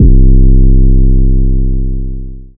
DEEDOTWILL 808 34.wav